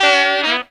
HARM RIFF 7.wav